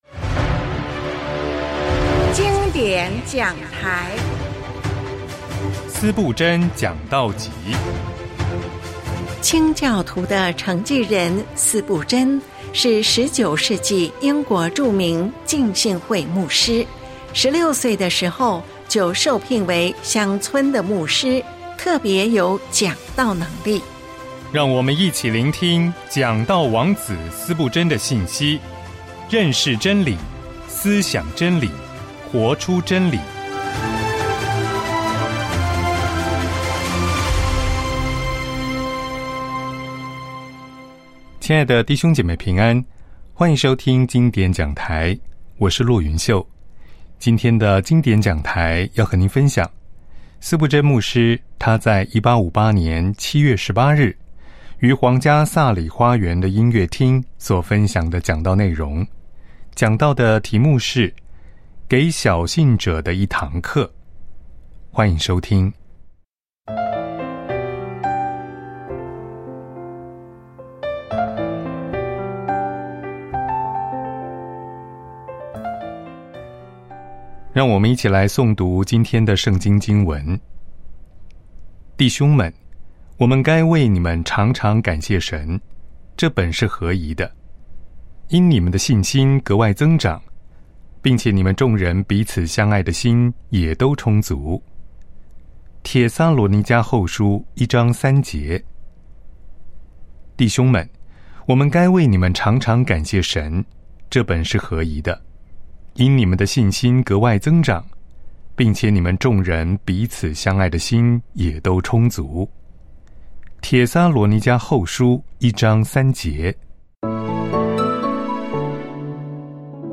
播讲教会历史中影响世代的精湛讲道内容。